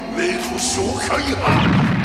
He makes a long, loud roar, and then gathers energy in his mouth, before firing it out: